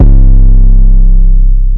808 10 [ monster ].wav